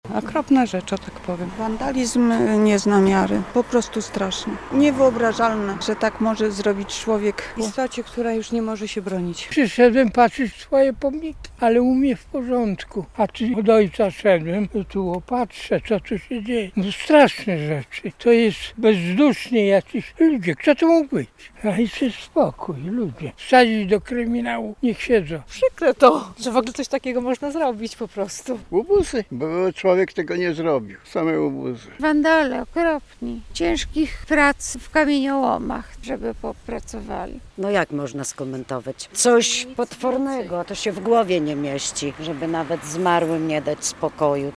Ludzie odwiedzający cmentarz w Łomży są oburzeni aktem wandalizmu - relacja